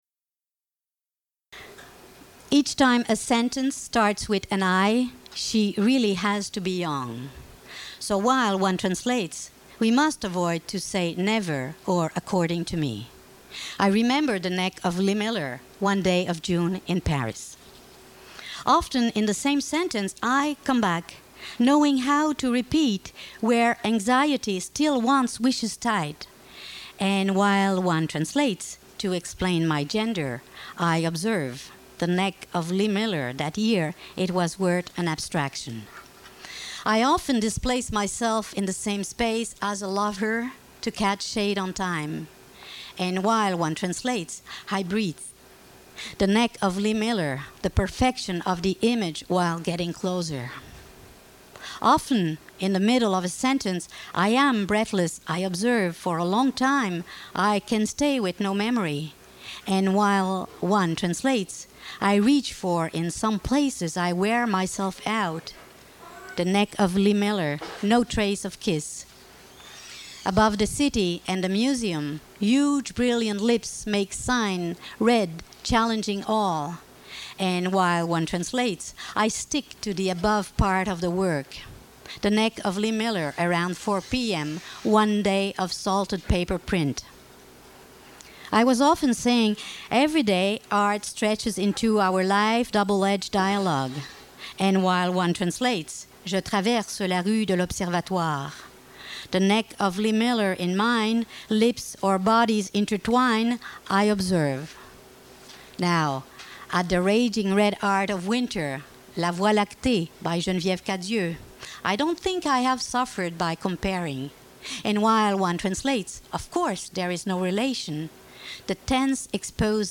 The sequence culminates with the incantation of that name, that phrase, the syntax slightly varied for the pleasure another enunciation affords, the French, suppressed by not inaudible here in the author's own translation, jubilantly returning in the emphatic "cou" that precedes a "merci" to the audience and the release of laughter and applause.
More from Brossard's Segue reading of 5 May 2001 at PennSound.